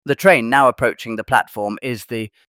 We deliver clear, professional, and neutral-sounding English announcements using a custom-trained AI voice that replicates the style of real British transport systems.
train-approaching-the-platform.mp3